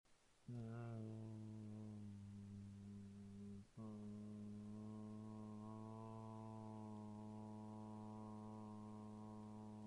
描述：sonido de cinta desgarrandose y luego siendo utilizada